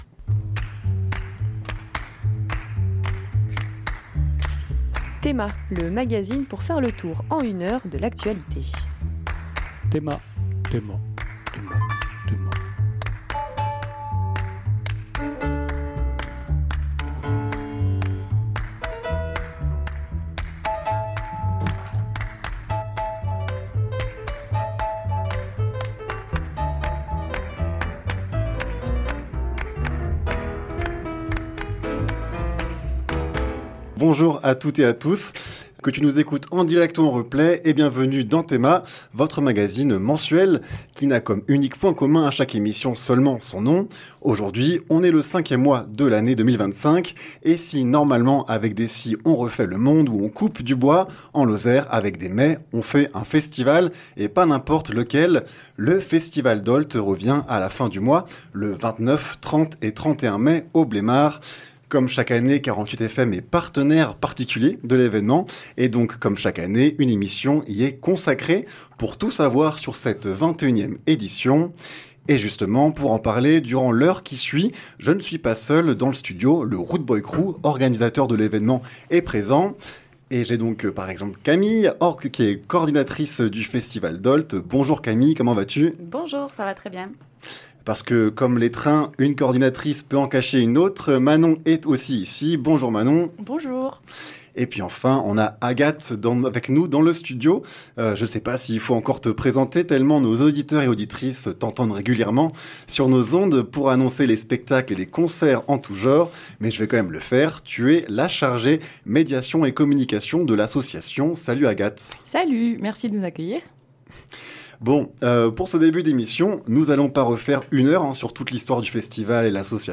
en direct de 48FM